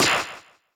New Air Rally Sounds